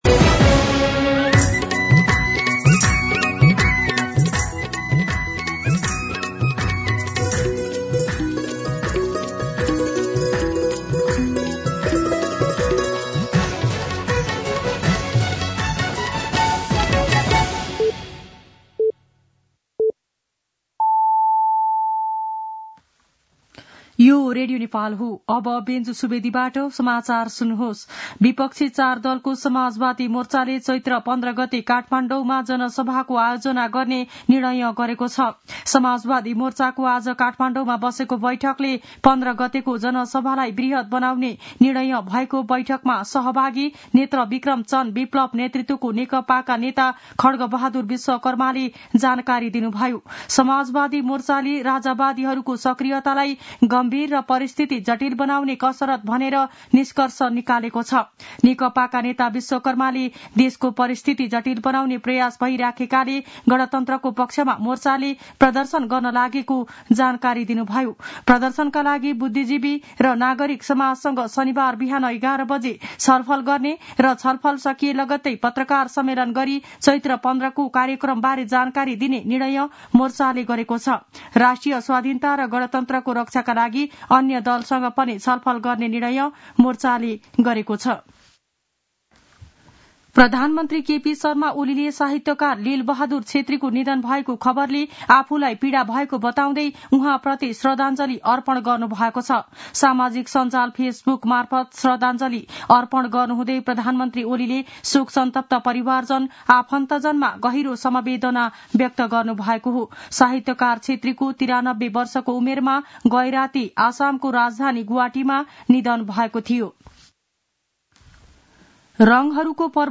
दिउँसो १ बजेको नेपाली समाचार : १ चैत , २०८१
1pm-News-01.mp3